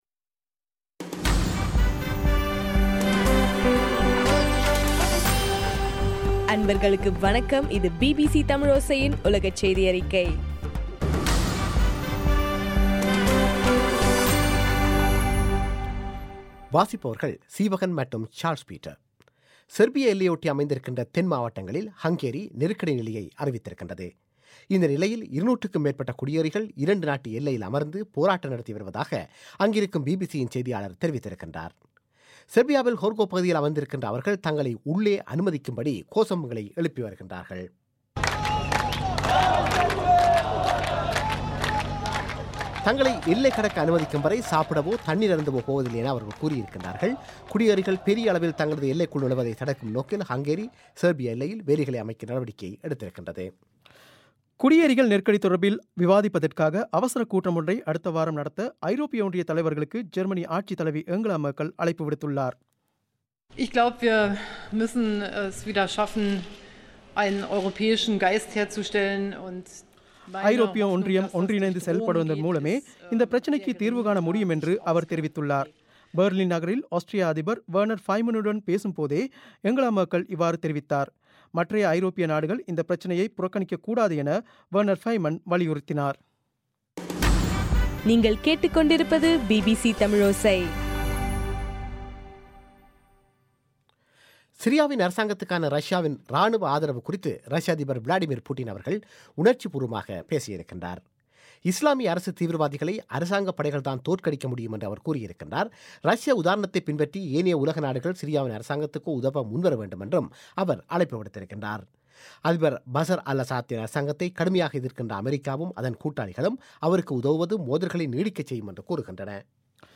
இன்றைய (செப்டம்பர் 15) பிபிசி தமிழோசை செய்தியறிக்கை